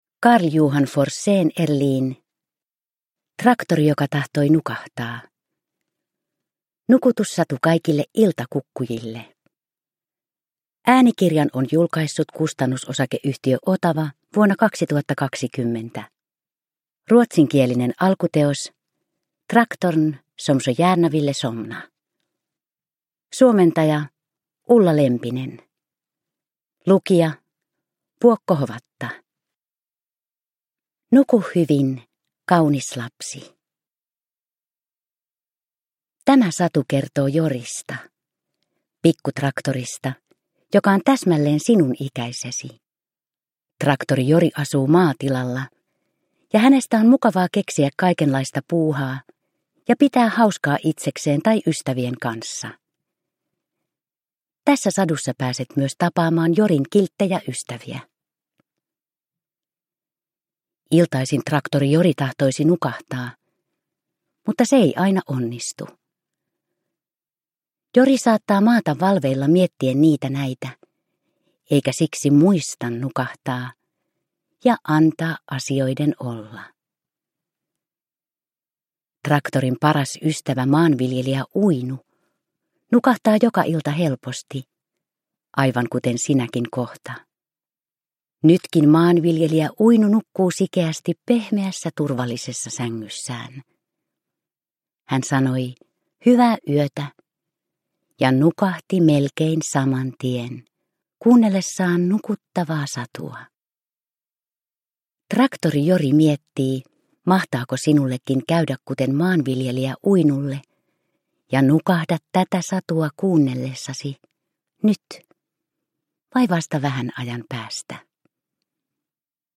Traktori joka tahtoi nukahtaa – Ljudbok – Laddas ner